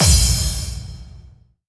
VEC3 FX Reverbkicks 18.wav